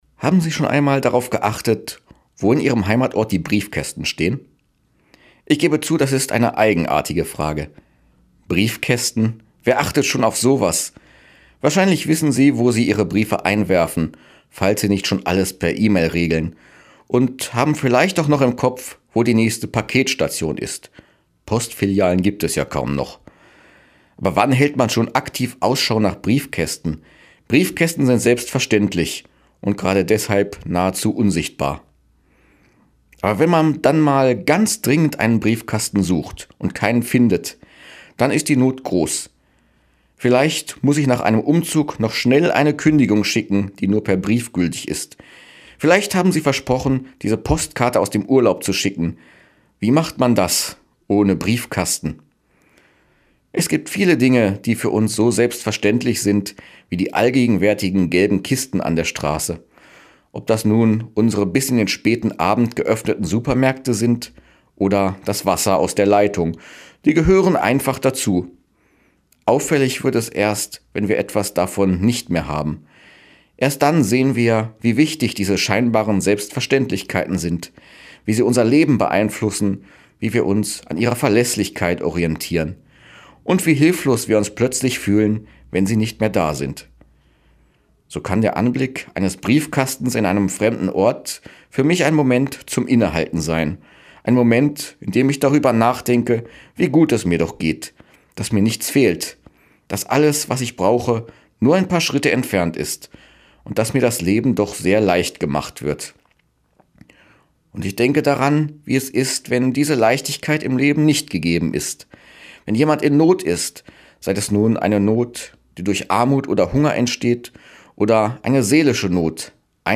Radioandacht vom 21. Juni